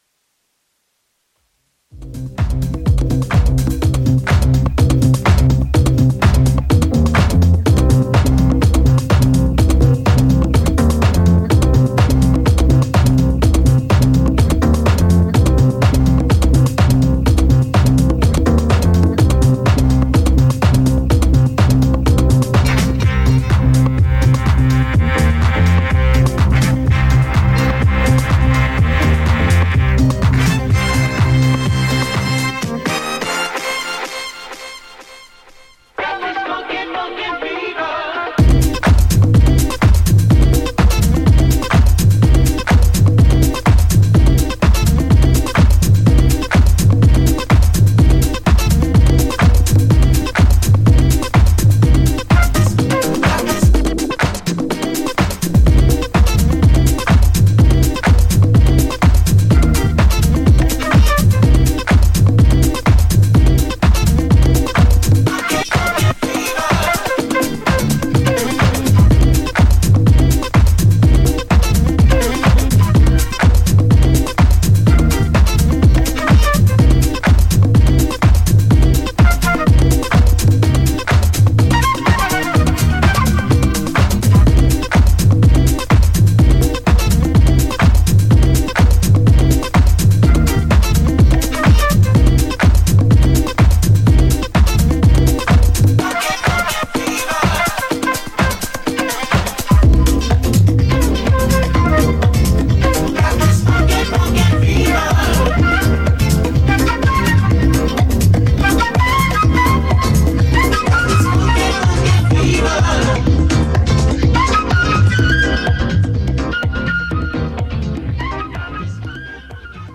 ジャンル(スタイル) DISCO / NU DISCO